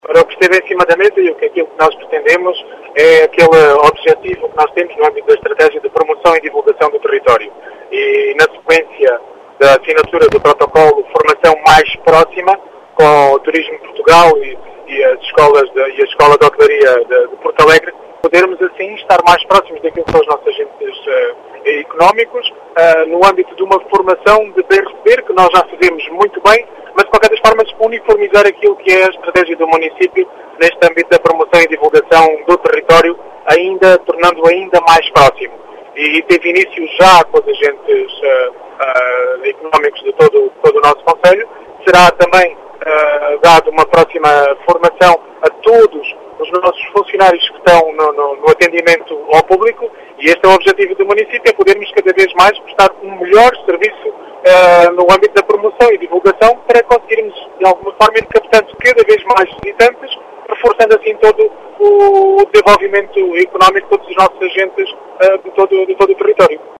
As explicações são do presidente da Câmara Municipal de Vidigueira, Rui Raposo, afirma que esta iniciativa insere-se na “ promoção e divulgação do território” levada a cabo pela autarquia.